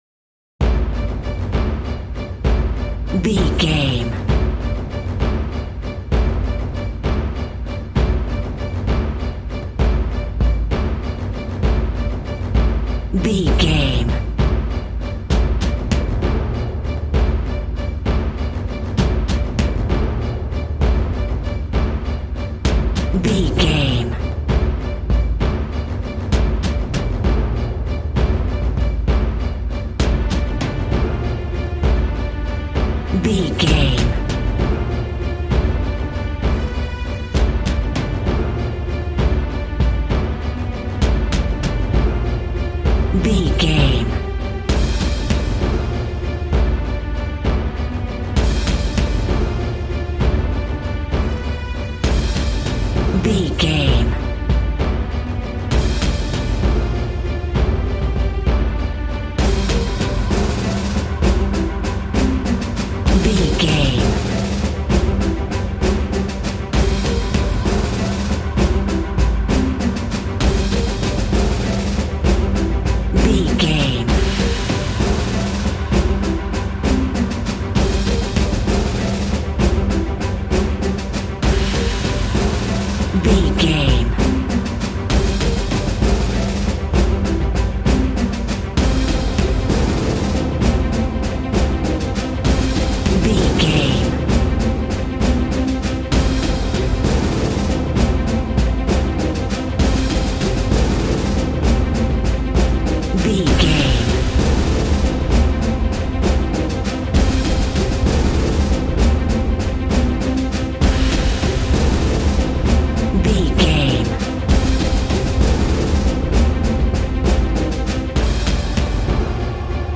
Epic action music with a celtic feel.
Epic / Action
Fast paced
In-crescendo
Uplifting
Aeolian/Minor
dramatic
powerful
strings
brass
percussion
synthesiser